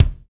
Index of /90_sSampleCDs/Northstar - Drumscapes Roland/KIK_Kicks/KIK_H_H Kicks x
KIK H H K05R.wav